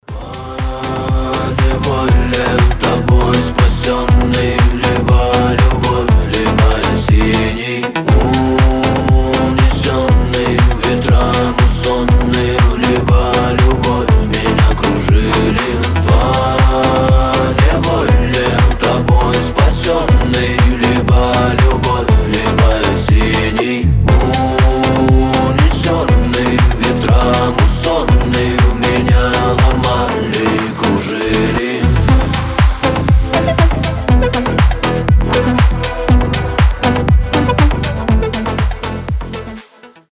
• Качество: 320, Stereo
deep house
атмосферные
красивый мужской голос
стильные